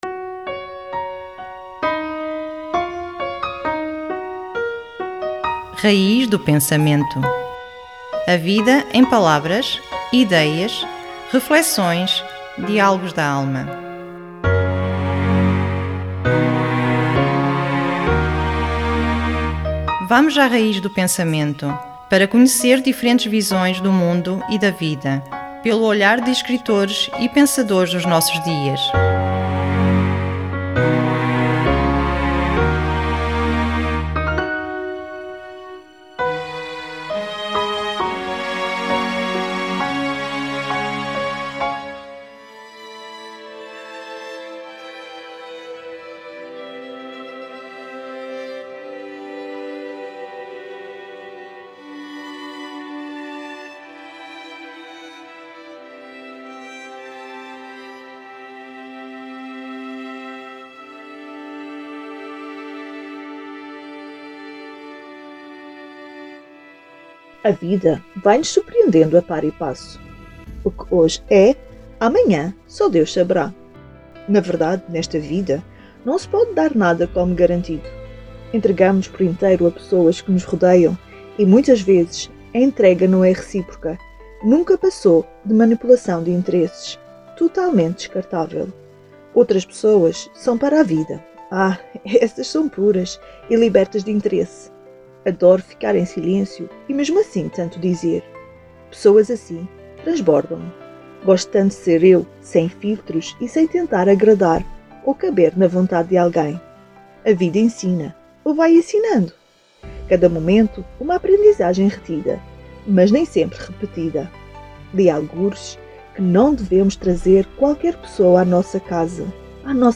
Uma reflexão